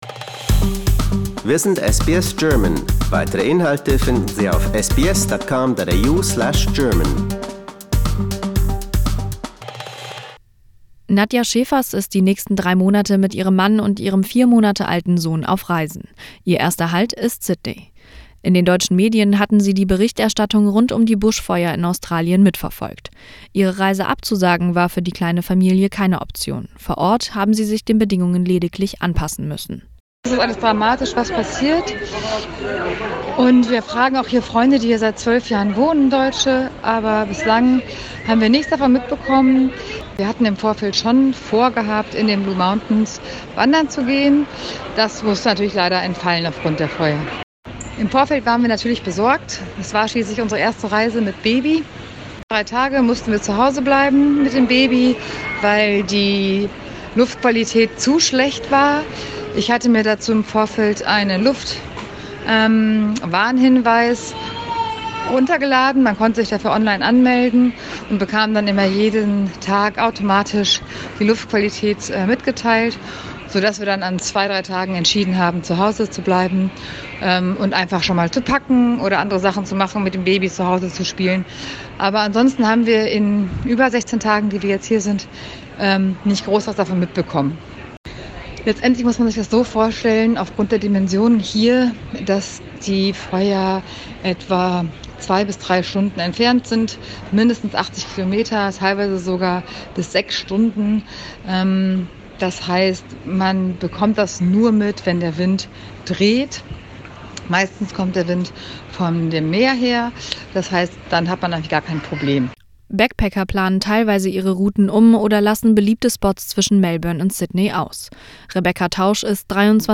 This may affect the tourism industry in the longer term. We spoke to German travelers about how they perceive the current situation.